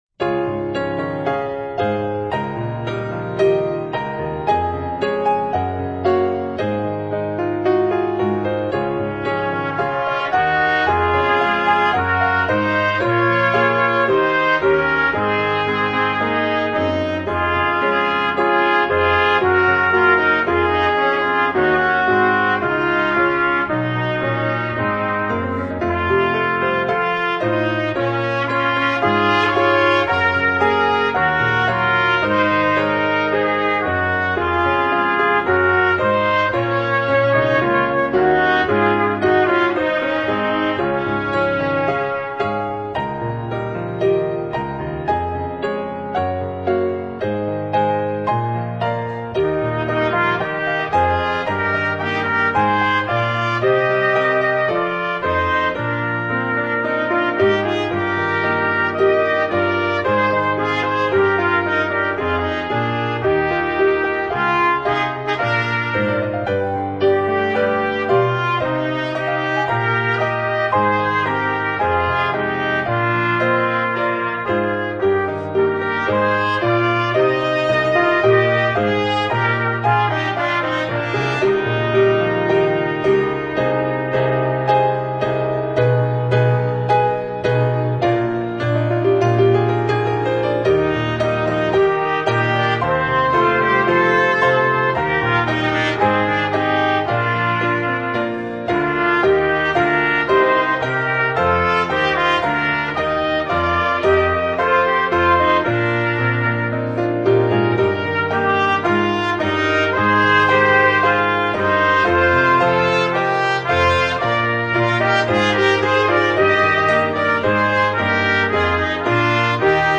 Voicing: Instrument Duet